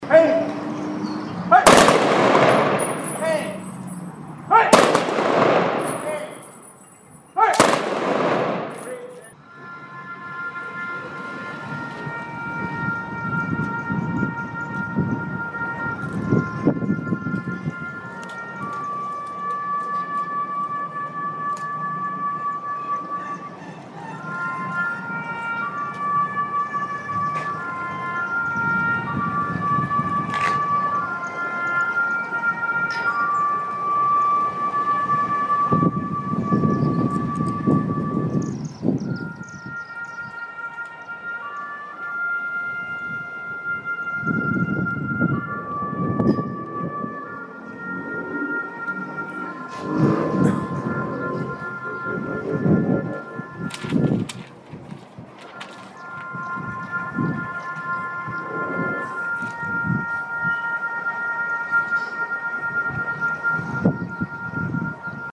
followed by a 21-gun salute and the playing of Taps.
2025 Princeton Memorial Day Service